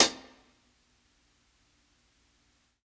60s_HH_SOFT_2.wav